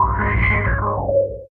69 MACHINE-R.wav